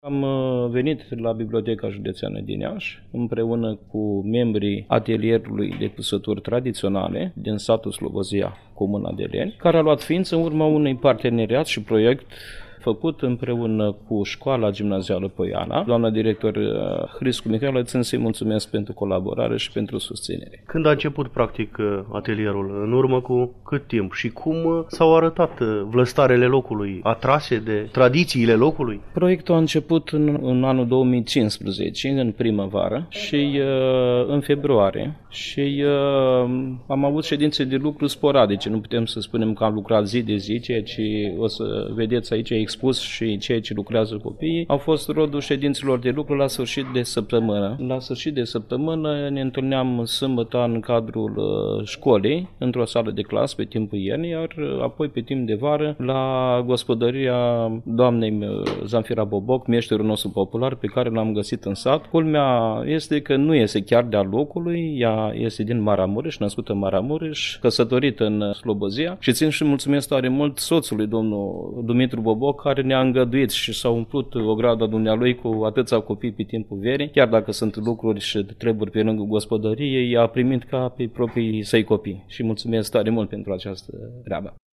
I-am întâlnit, zilele trecute, cu emoție, bucurie și energie pozitivă, în incinta Bibliotecii Județene “Gh. Asachi” Iași, unde au venit să ne prezinte, o mică parte din valoarea tradiților și bogăției lor artistice, reprezentative pentru vatra etnofolclorică Deleni (Hârlău), Iași.